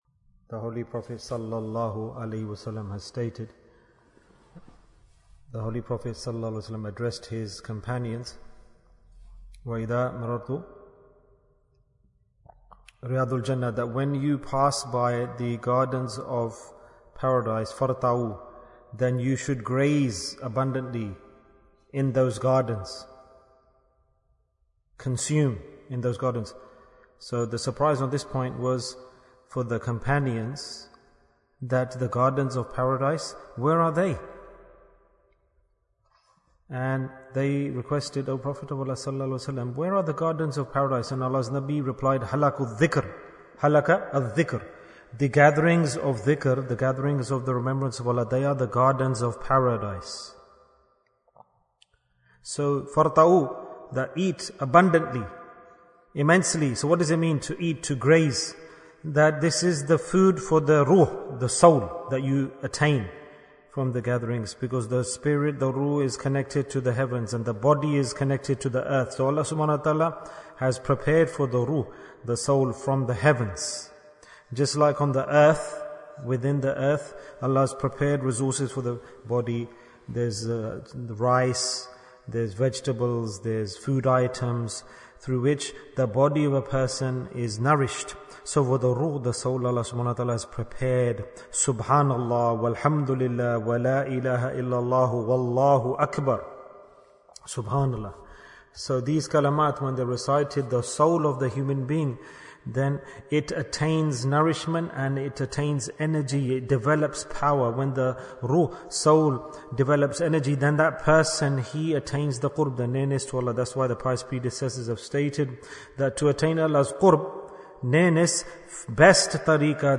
Paradise in Dunya Bayan, 8 minutes26th May, 2023